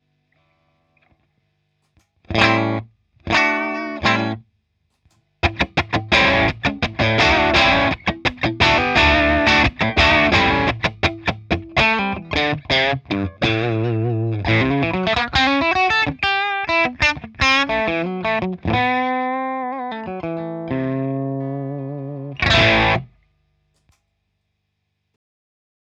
“Dynamic crunch” bridge single coil